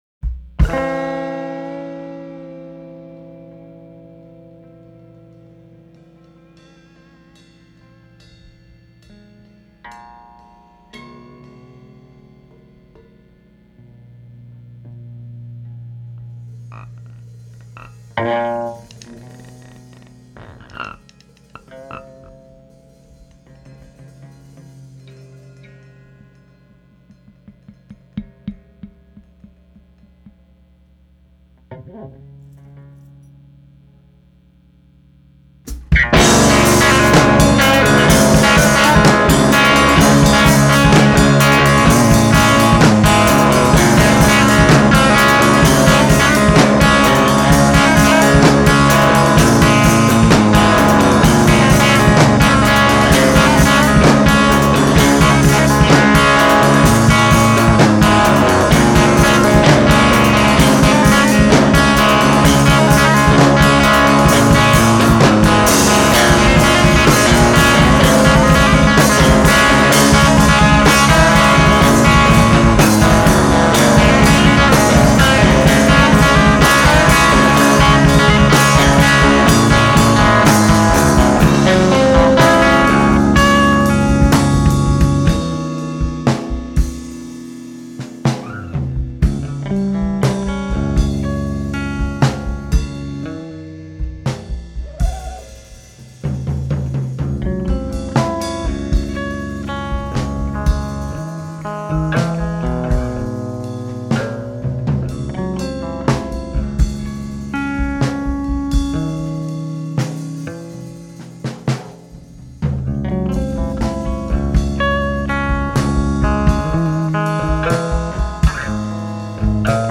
instrumental act
The two-piece band